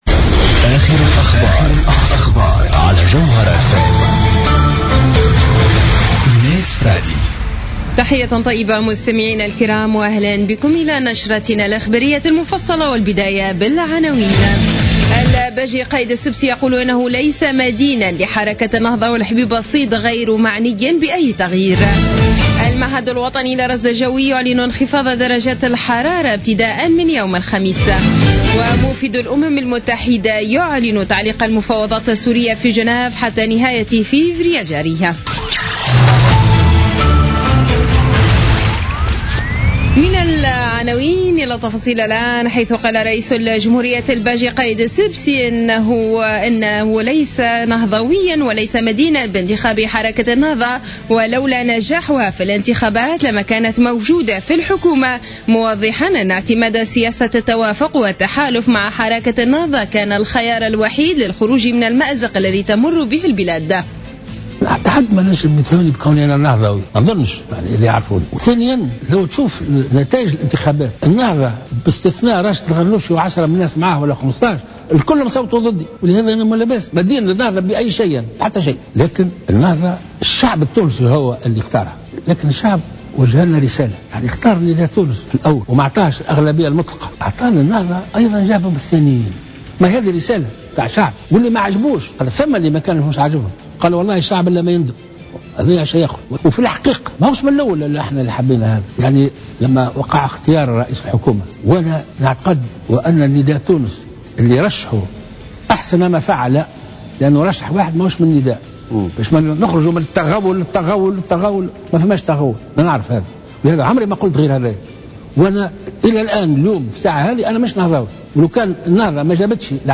نشرة أخبار منتصف الليل ليوم الخميس 4 فيفري 2016